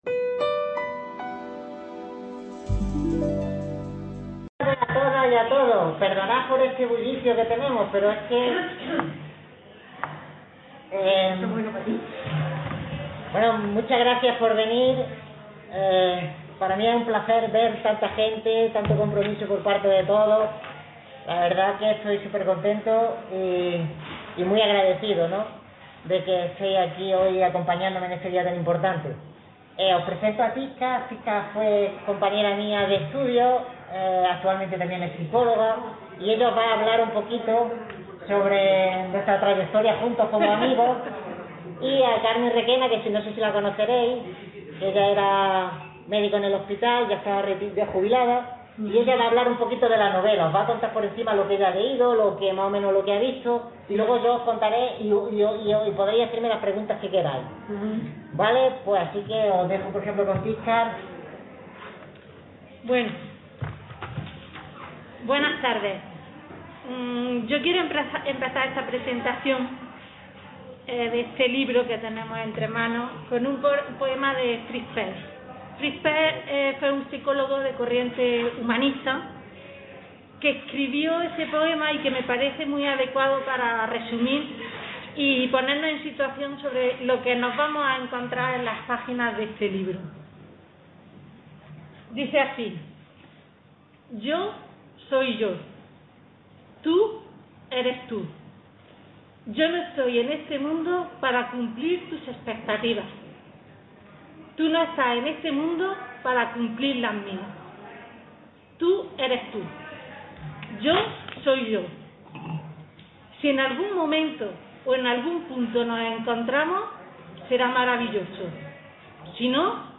Description Se trata de la presentación de una novela urbana, contemporánea y realista, basada en una historia de encuentros y desencuentros amorosos. El amor, el desamor, la intriga y la acción atrapan al lector a través de los personajes y sus peripecias.